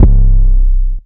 808 (No Lie).wav